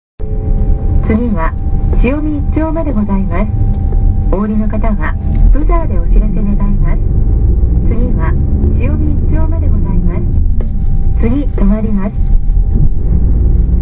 音声合成装置 指月電機製作所